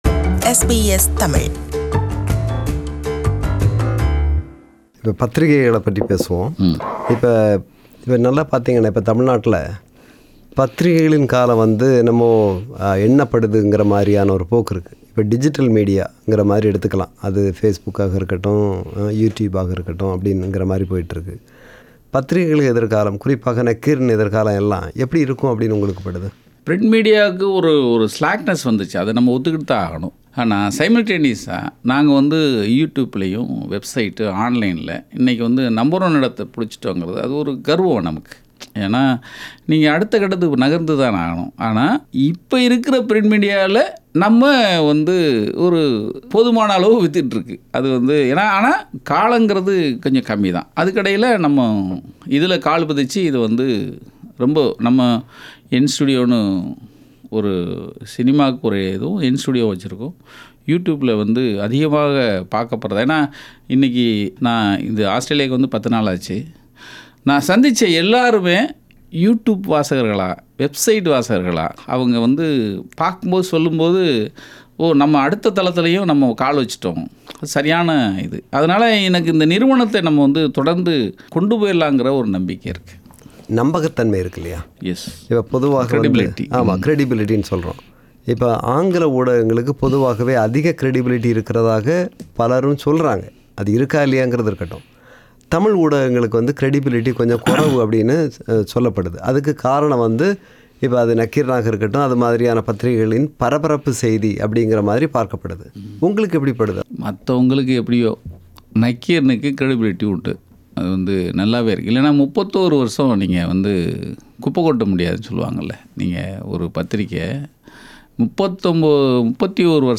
Interview with Nakkheeran Gopal – Part 3